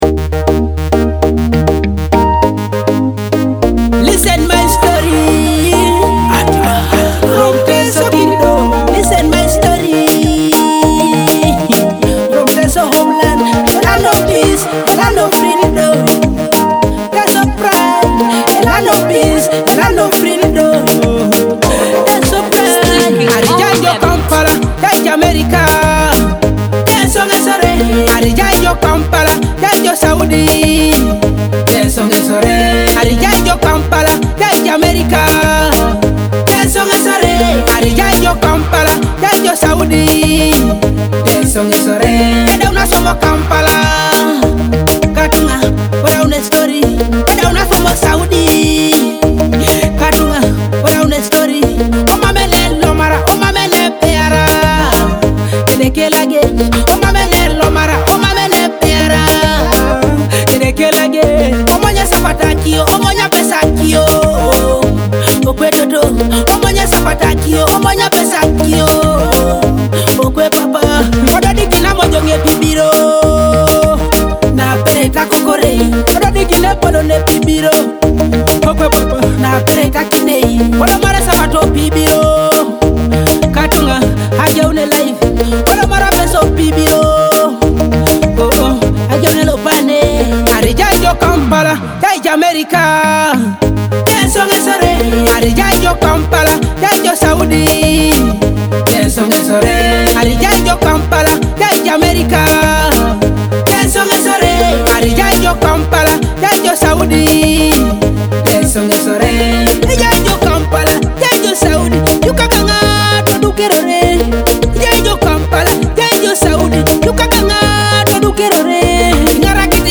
a vibrant danchall hit